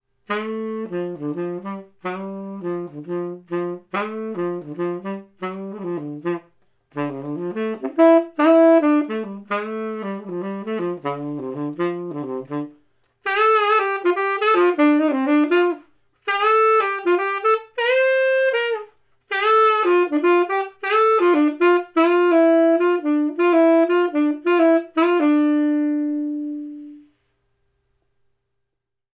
Spaced cardiods
2 x 1/2″ Cardiods, spaced 1,5 m.
Spaced, Cardioid Sax
Spaced_Cardioid_Sax.wav